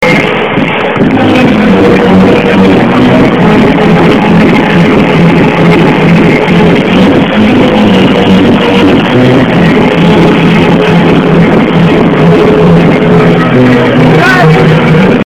Heard this song from Global Gathering UK in Godskitchen area
Hey, i heard this song in the Godskitchen are of Global Gathering UK and would be much appreciated if someone helped me out with it,